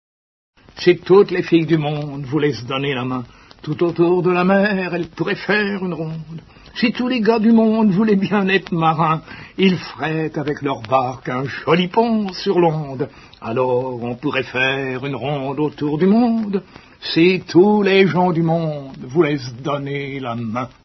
/wp-content/uploads/2011/01/rondeautourdumonde.mp3 dit par l’auteur Paul FORT ( Ballades françaises , 1922-1958)